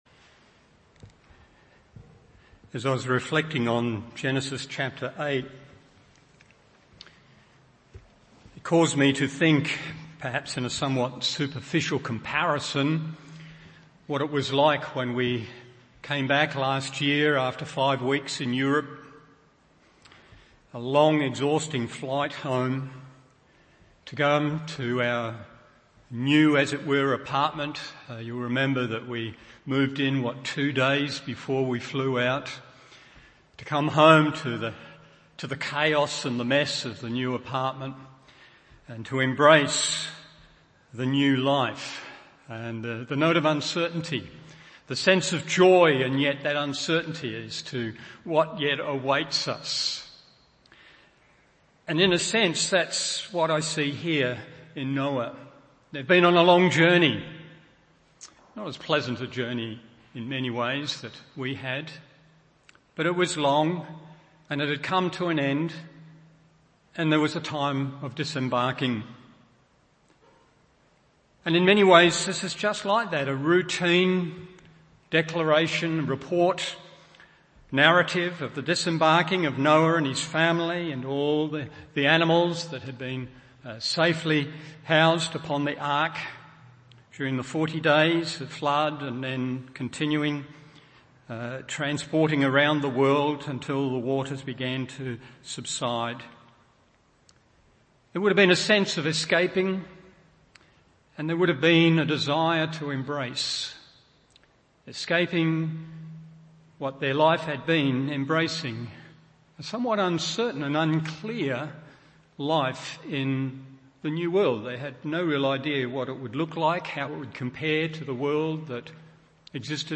Morning Service Genesis 8:6-20 1. The Note of Faith 2. The Note of Obedience 3. The Note of Worship…